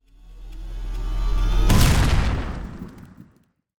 etfx_explosion_chargeup.wav